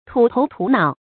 土頭土腦 注音： ㄊㄨˇ ㄊㄡˊ ㄊㄨˇ ㄣㄠˇ 讀音讀法： 意思解釋： 指行為、舉止、服飾等不合時尚 出處典故： 清·吳趼人《二十年目睹之怪現狀》第三回：「誰知他 土頭土腦 的，信是一句話。」